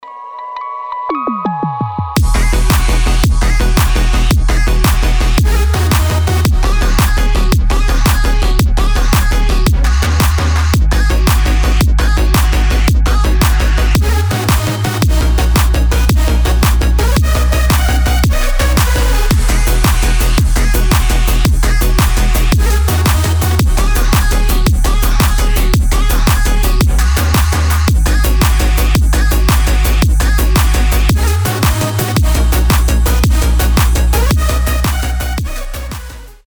• Качество: 320, Stereo
милые
мелодичные
Electronic
Midtempo
Drumstep
Стиль: драмстеп, мидтемпо